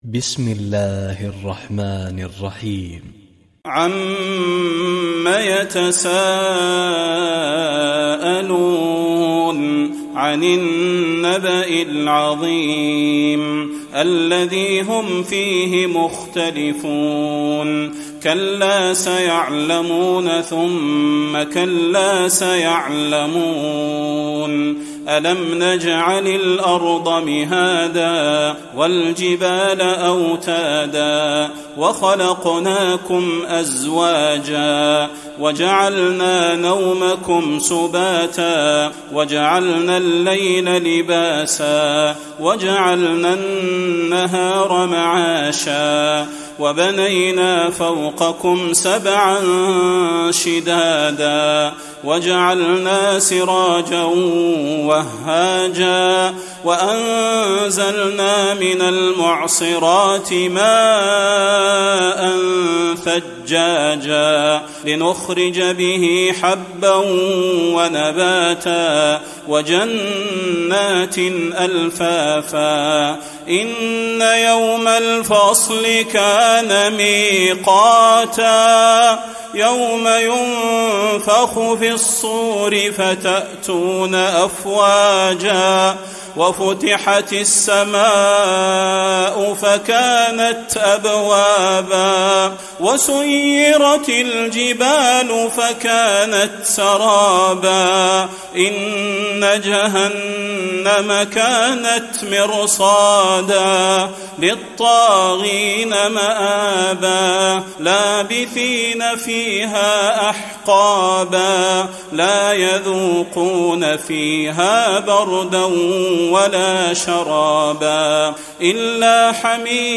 تراويح ليلة 29 رمضان 1435هـ من سورة النبأ الى الطارق Taraweeh 29 st night Ramadan 1435H from Surah An-Naba to At-Taariq > تراويح الحرم النبوي عام 1435 🕌 > التراويح - تلاوات الحرمين